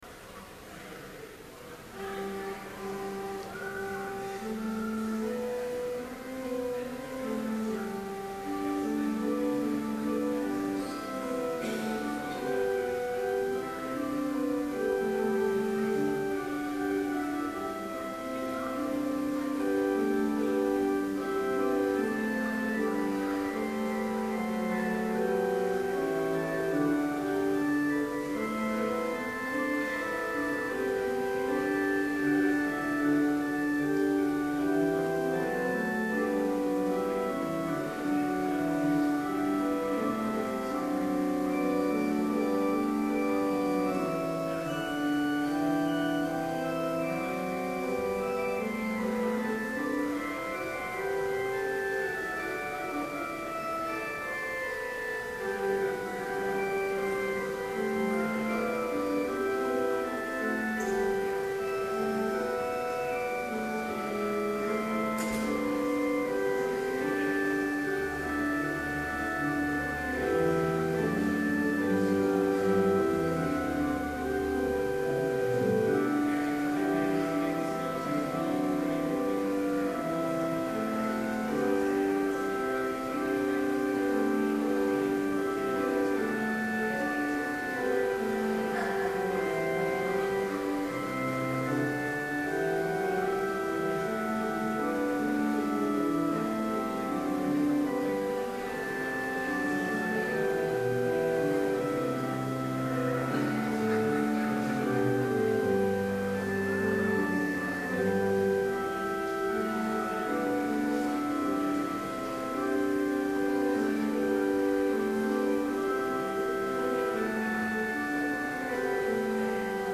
Complete service audio for Chapel - March 26, 2012
Order of Service Prelude Hymn 150, vv. 2 & 7, A little Son, the … Scripture Reading: Luke 1:35-38 Homily Prayer Hymn 268, vv. 1, 4 & 5, On Mary, Virgin Undefiled Benediction Postlude